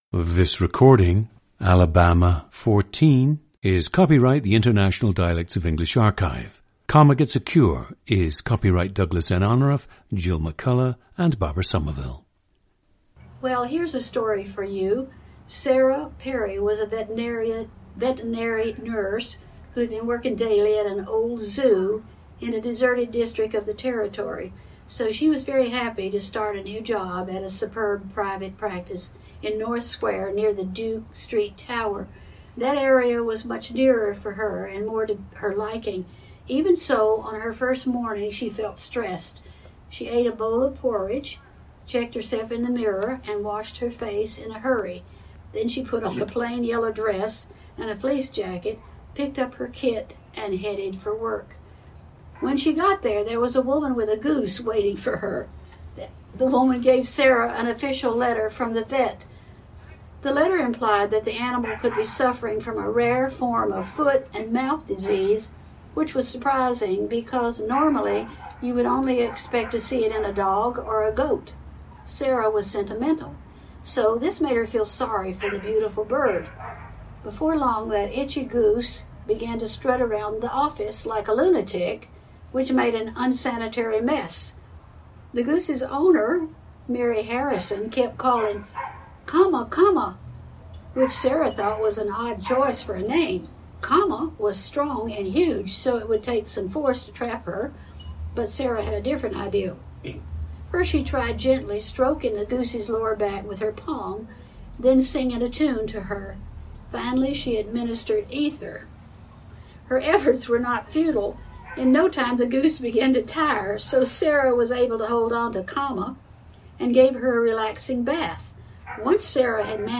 PLACE OF BIRTH: Elkwood, Madison County, Alabama
GENDER: female
This angered her, and she was motivated to never tone down her Southern accent while working in education.
• Recordings of accent/dialect speakers from the region you select.
The recordings average four minutes in length and feature both the reading of one of two standard passages, and some unscripted speech.